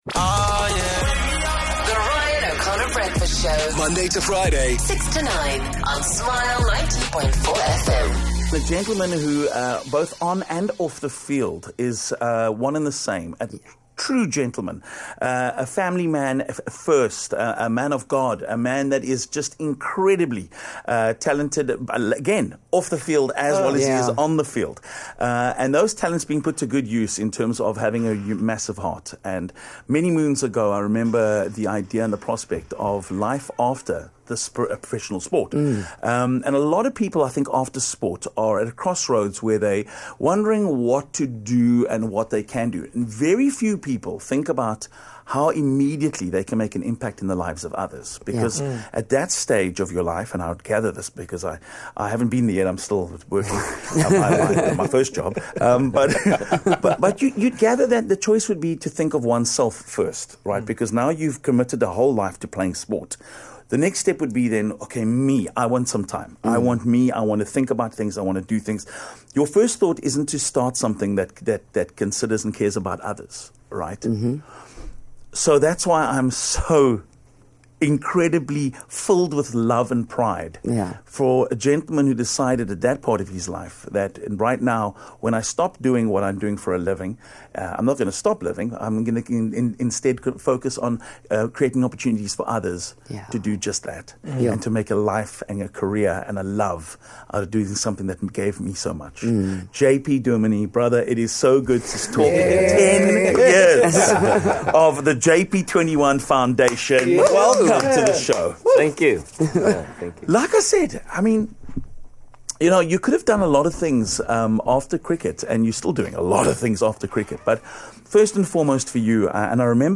Over the past decade, the foundation has made significant strides in nurturing young learners, supporting community initiatives, creating opportunities for learners to thrive, learn and dream. JP Duminy joined us in studio today for a chat about the journey his foundation has been on and their plans beyond these 10 years.